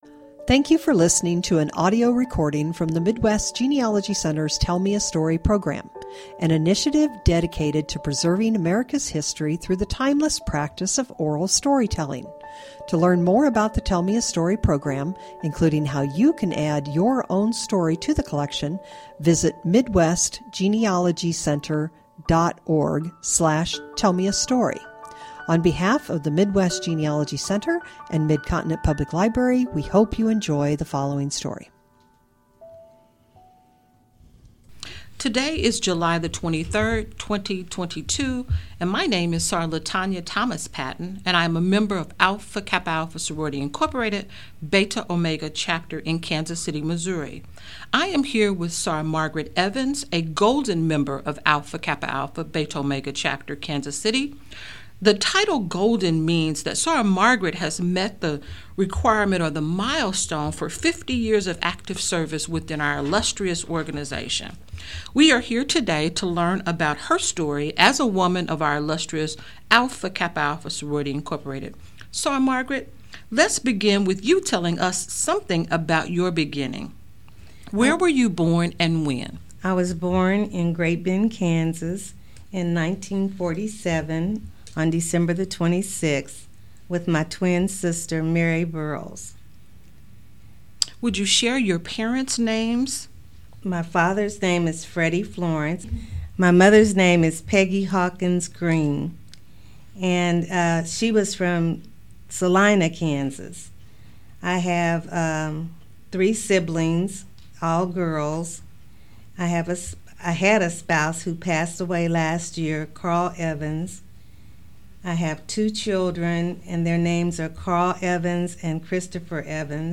Genealogy Family history Oral history